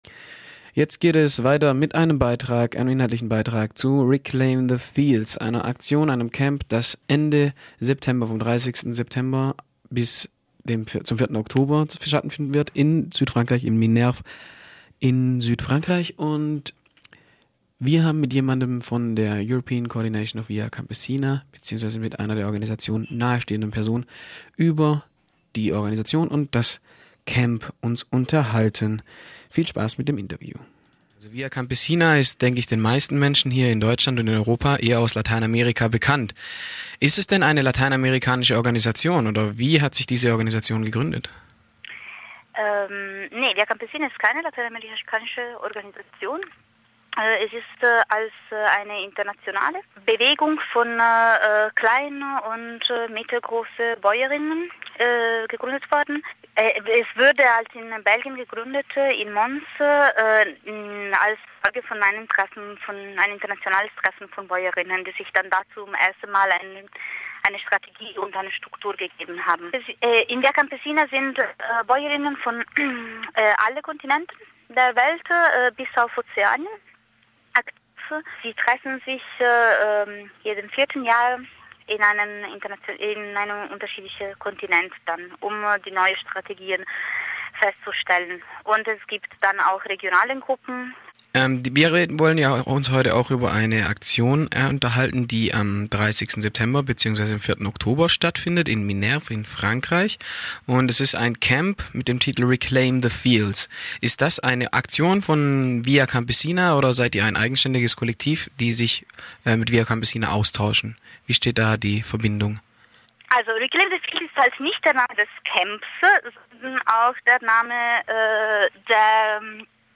Ich habe mich mit einer Organisatorin des europäischen Reclaim the Fields Cammp unterhalten, dass vom 30 September bis 04.Oktober in Minerve Frankreich stattfinden wird.